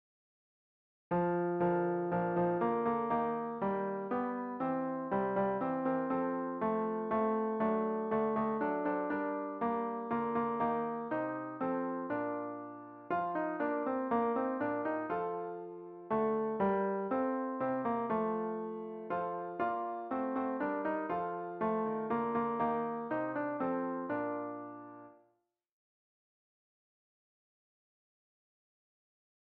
Fichiers pour répéter :
We wish you a merry christmas Tenor